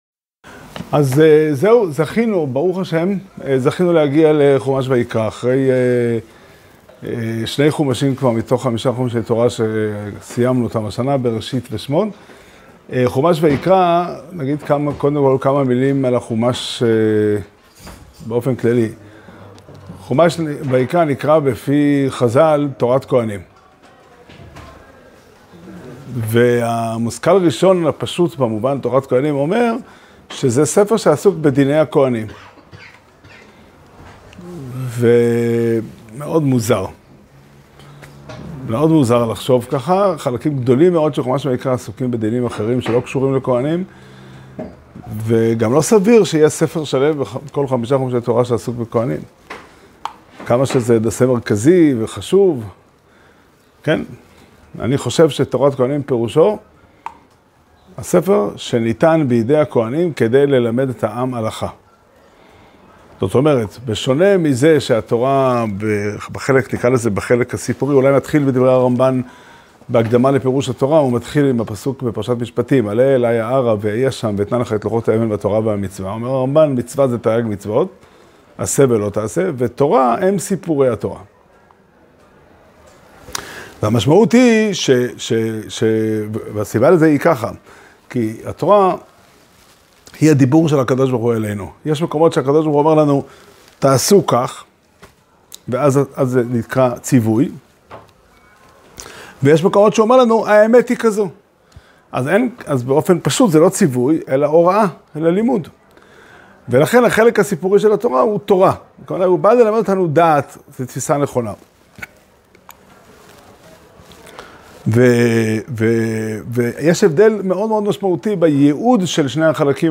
שיעור שנמסר בבית המדרש פתחי עולם בתאריך ל' אדר א' תשפ"ד